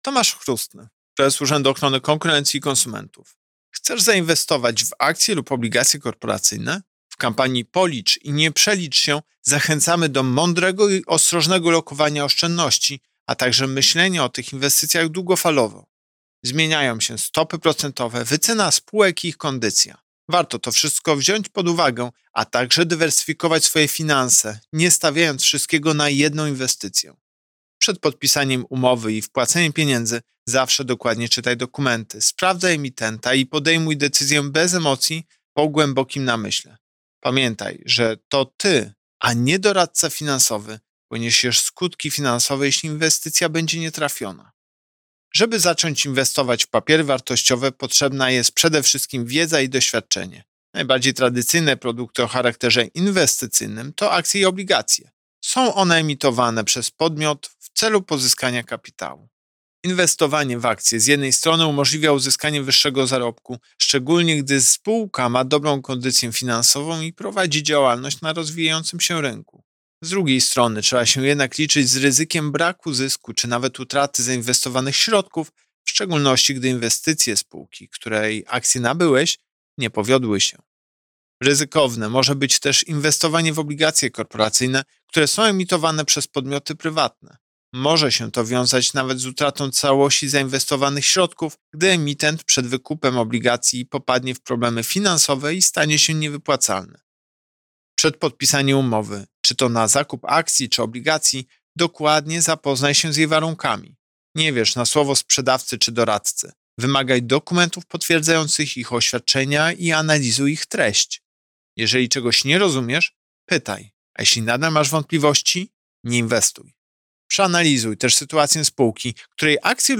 Pliki do pobrania: Komunikat prasowy UOKiK z 13 września 2021 r..docx (126.26 KB) Wypowiedź Prezesa UOKiK Tomasza Chróstnego z 13 września 2021 r..mp3 (7.05 MB)